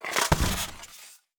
Fall on Ice Normal.wav